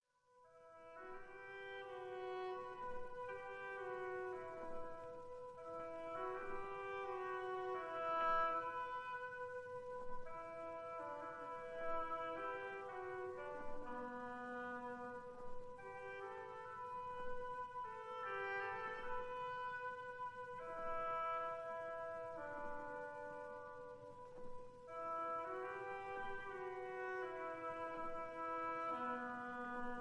This is a 1957 recording made in the Town Hall Annexe